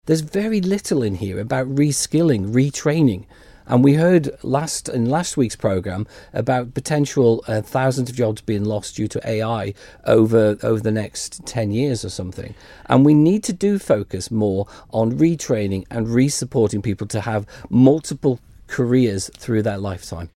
He told Manx Radio there needs to be a focus on ensuring workers have the skills for more than one profession: